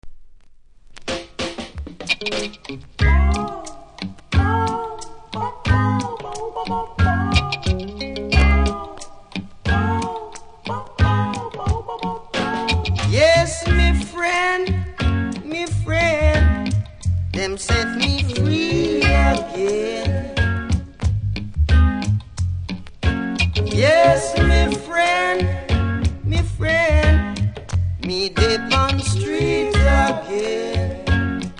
途中少しプレノイズありますがプレイは問題無いレベル。